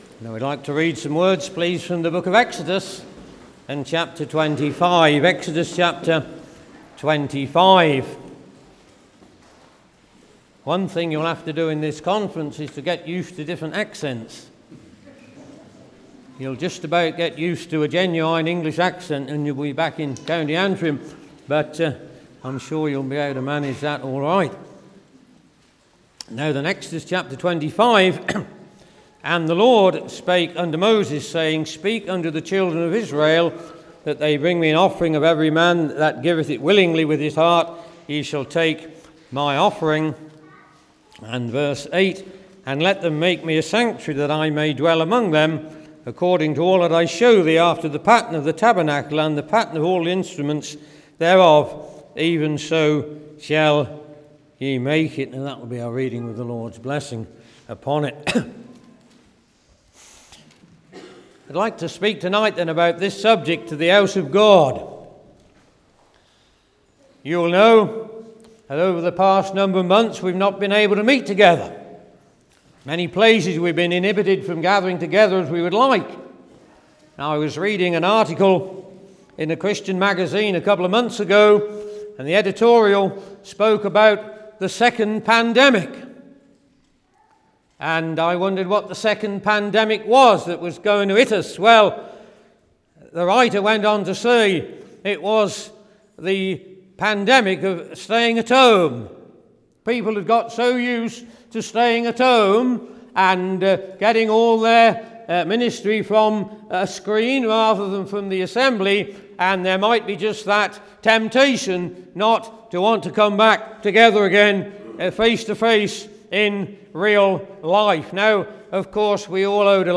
2022 Easter Conference